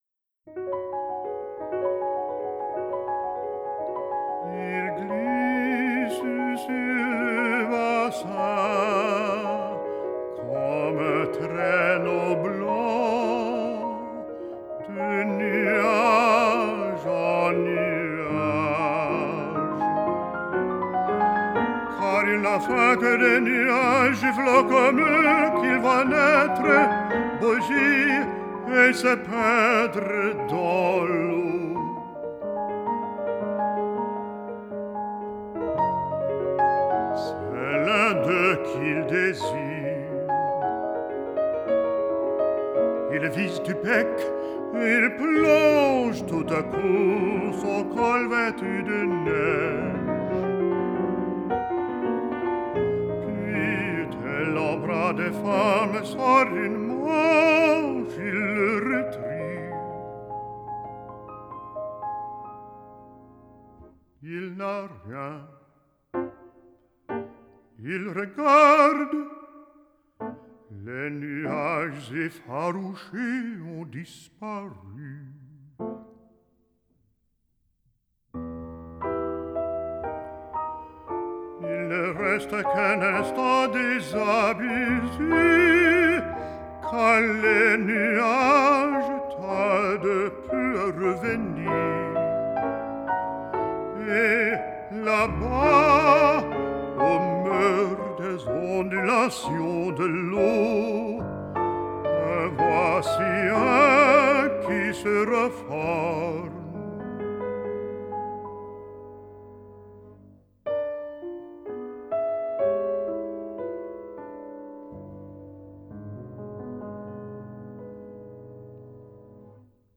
Baritone
Piano